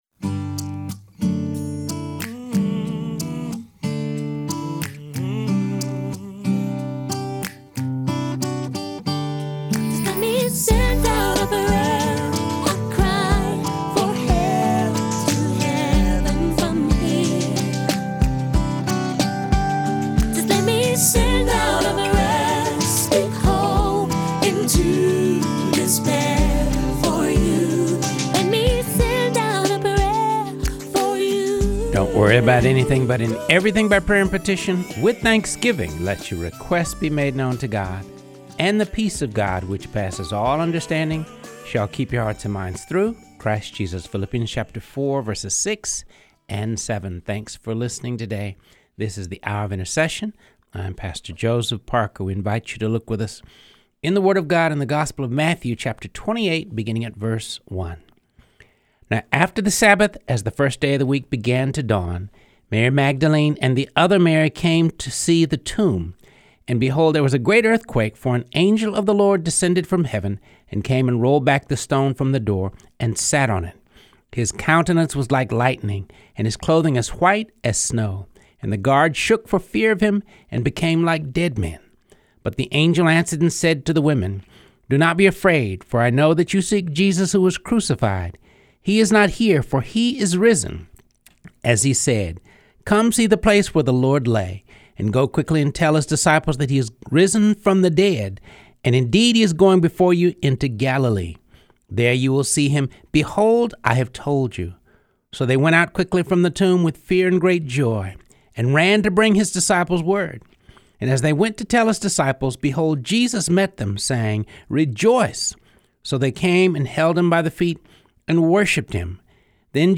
Teaching: The Call to Be a Hearer and Doer of the Word